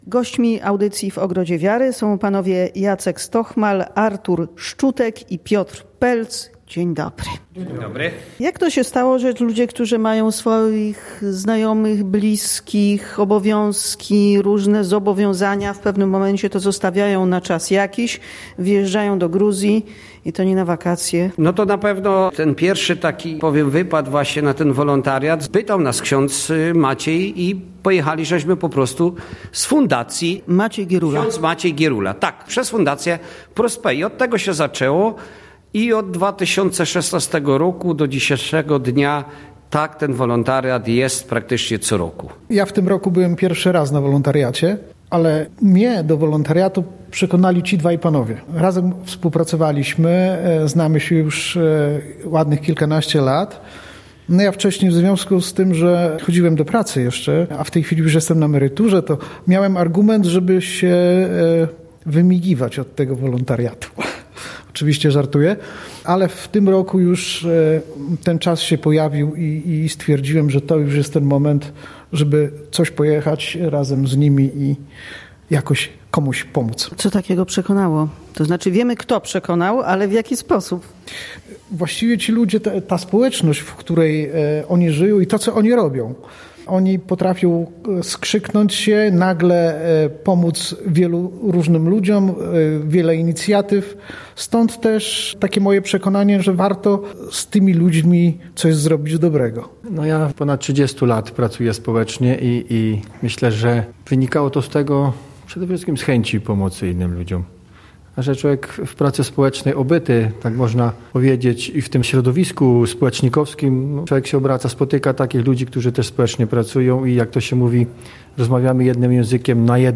Wolontariusze byli gośćmi audycji W ogrodzie wiary.